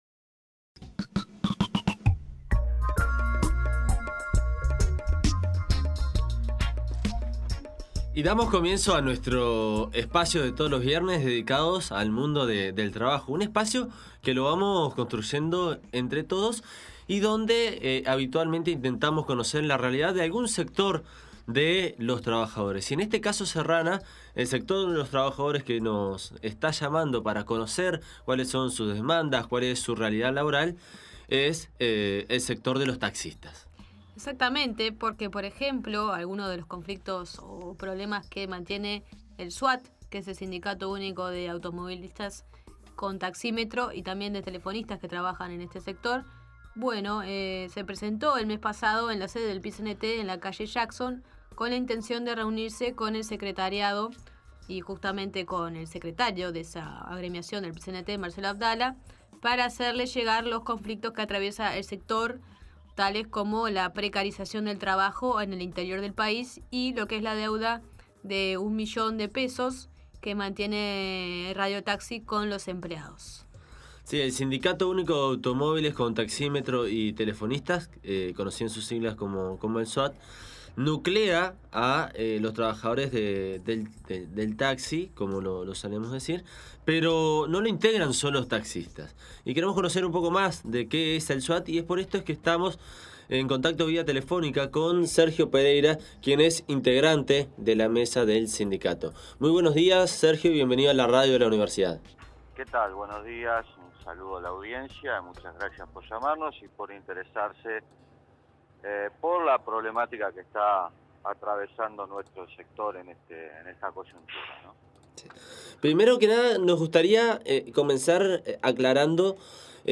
En La Nueva Mañana de día de hoy, nos aproximamos al mundo de los trabajadores del taxímetro. Entrevistamos